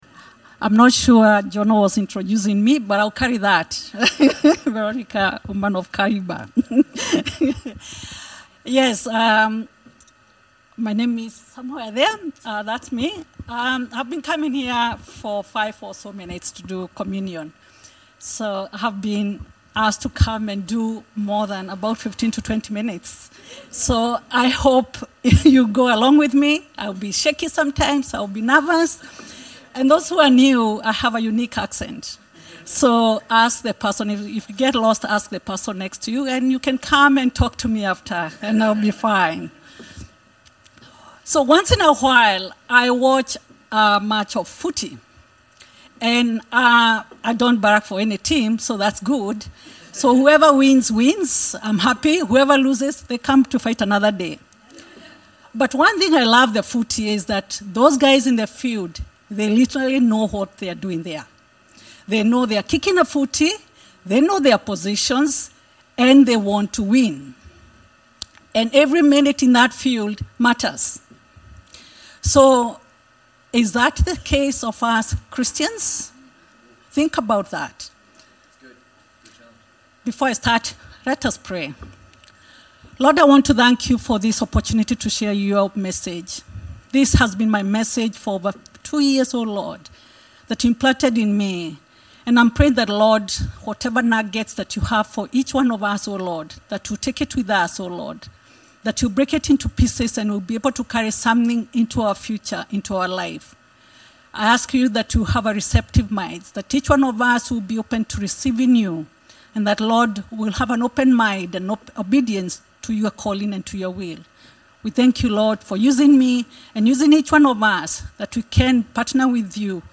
The Infinity Church Podcast - English Service | Infinity Church